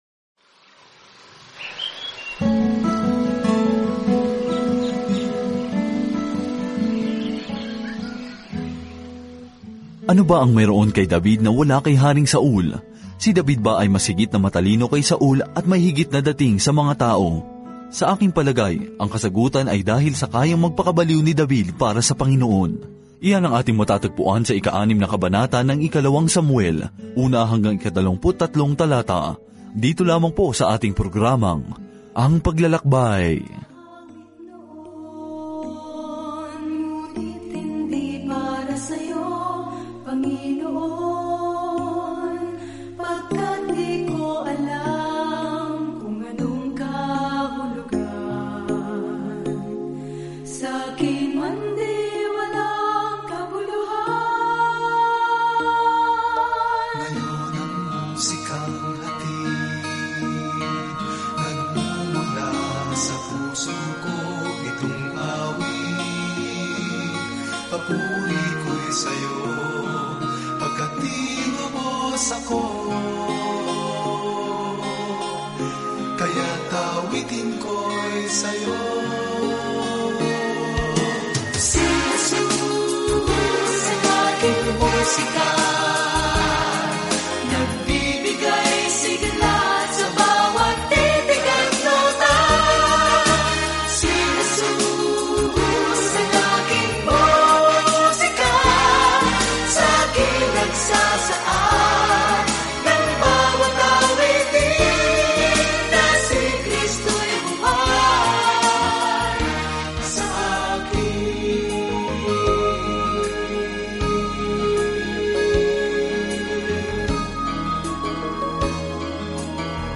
Banal na Kasulatan 2 Samuel 6 Araw 2 Umpisahan ang Gabay na Ito Araw 4 Tungkol sa Gabay na ito Ang kuwento ng relasyon ng Israel sa Diyos ay nagpapatuloy sa pagpapakilala ng mga propeta sa listahan kung paano nakikipag-ugnayan ang Diyos sa kanyang mga tao. Araw-araw na paglalakbay sa 2 Samuel habang nakikinig ka sa audio study at nagbabasa ng mga piling talata mula sa salita ng Diyos.